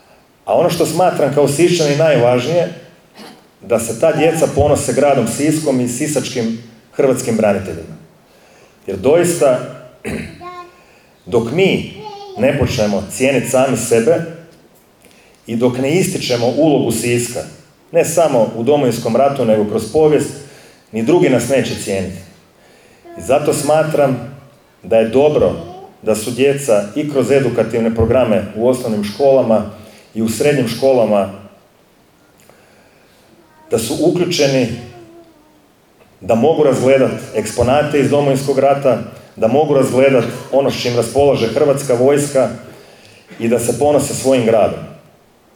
Gradonačelnik Orlić u svom je obraćanju posebno istaknuo zajedništvo kao ključ razvoja grada